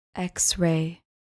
Pronounced: ECKS-ray